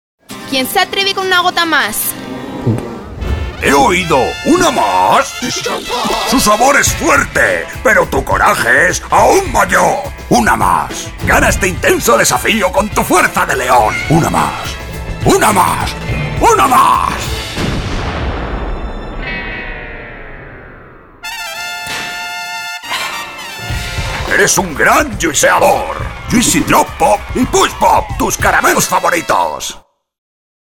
Sprechprobe: Werbung (Muttersprache):
Experienced Spanish home studio voiceover talent and producer.